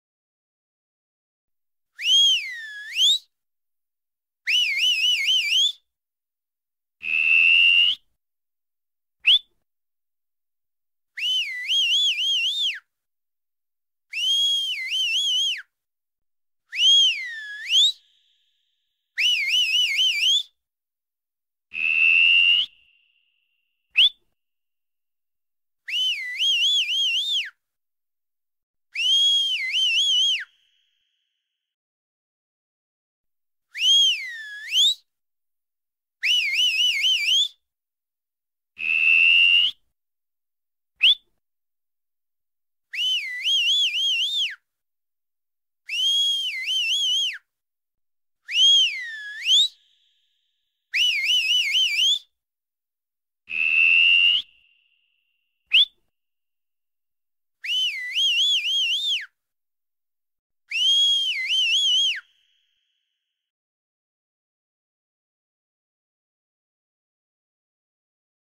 دانلود آهنگ سوت زدن 1 از افکت صوتی انسان و موجودات زنده
دانلود صدای سوت زدن 1 از ساعد نیوز با لینک مستقیم و کیفیت بالا
جلوه های صوتی